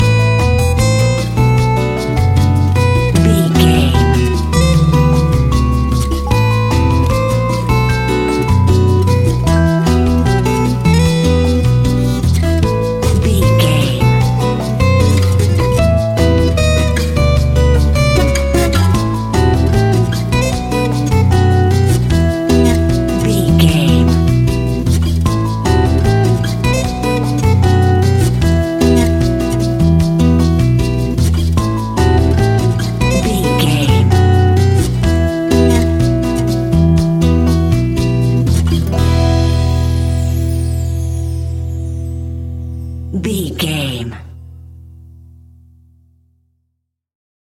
Aeolian/Minor
maracas
percussion spanish guitar